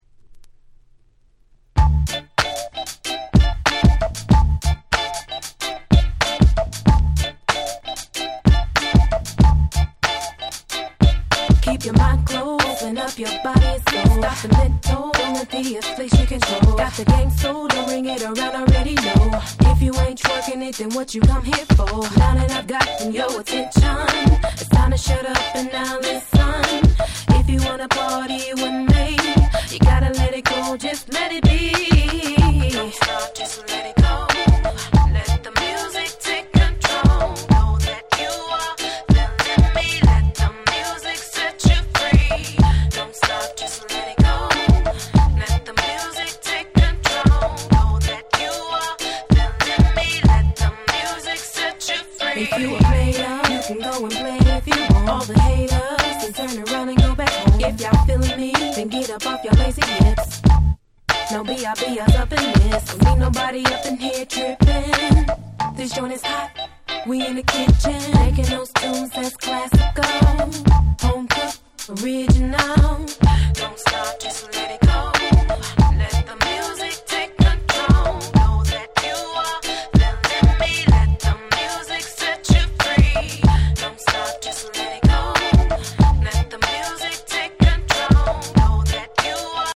03' Nice R&B / Neo Soul !!